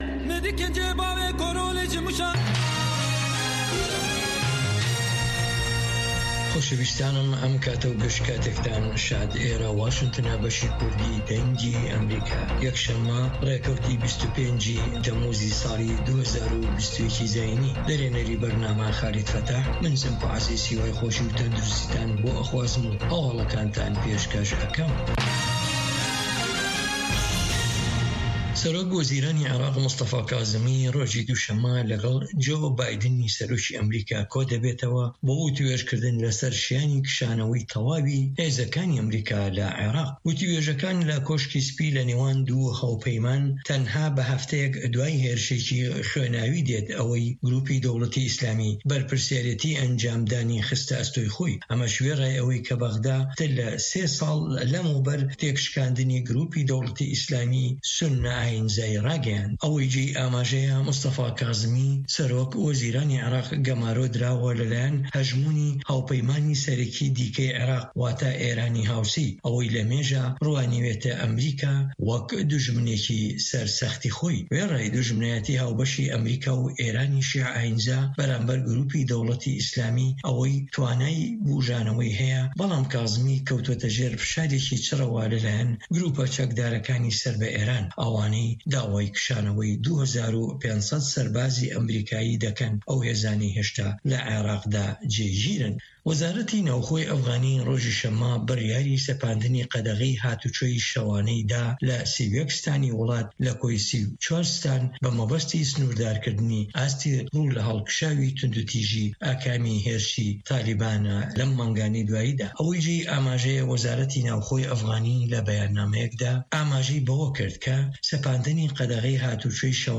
هه‌واڵه‌کان ، ڕاپـۆرت، وتووێژ.